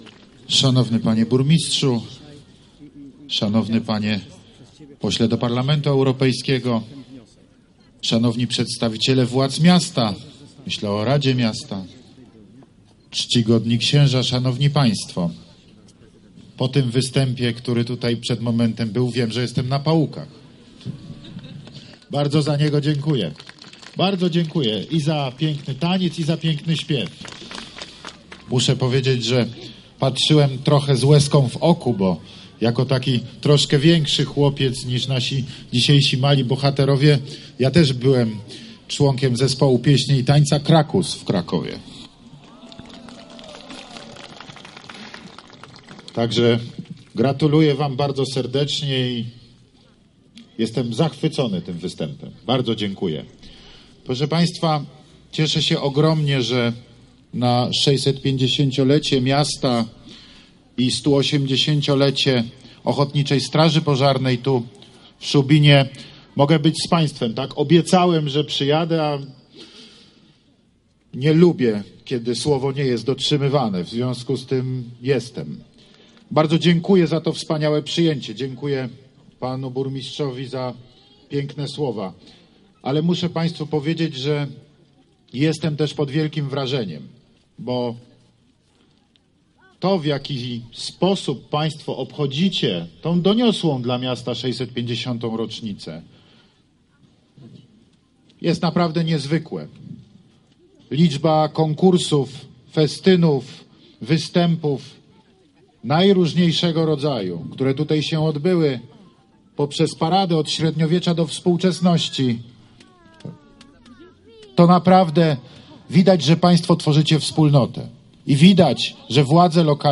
mówił na szubińskim rynku Prezydent Polski.